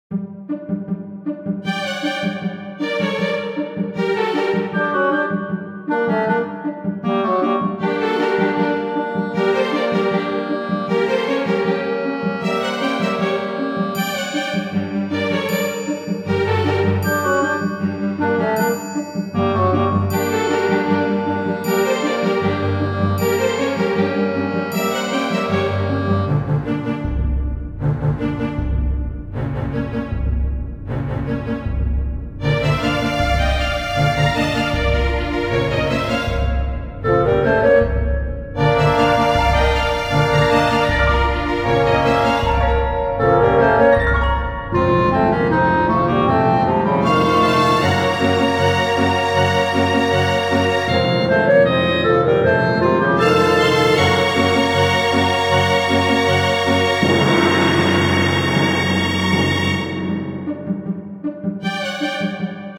緊迫